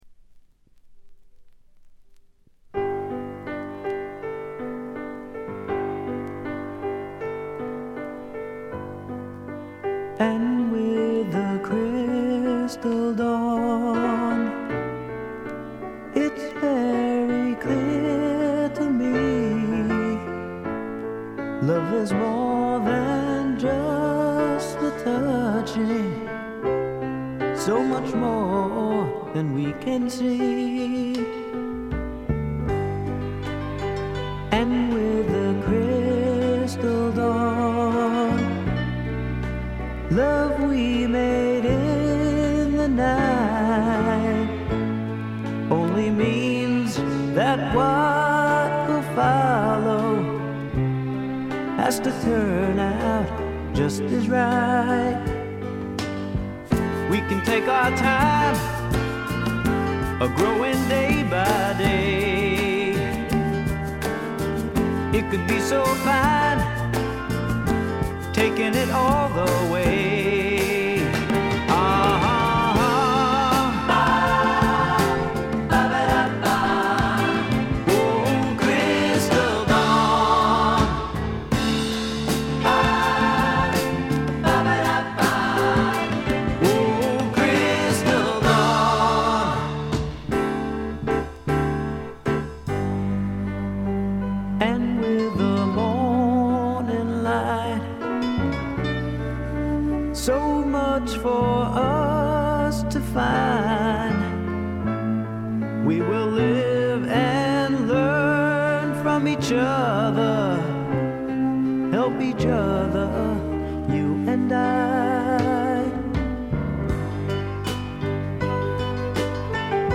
軽微なチリプチ少々。
定番的に聴きつがれてきたプリAOR、ピアノ系シンガーソングライター作品の大名盤です。
試聴曲は現品からの取り込み音源です。
Keyboards, Percussion, Vocals